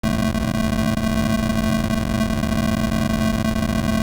noise_pitch.wav